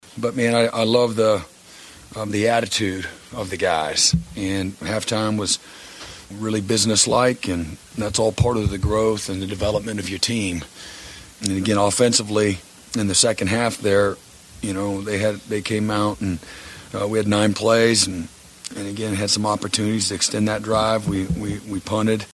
Oklahoma head coach Brent Venables after the win.